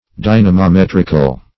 Meaning of dynamometrical. dynamometrical synonyms, pronunciation, spelling and more from Free Dictionary.
Dynamometrical \Dy`na*mo*met"ric*al\, a.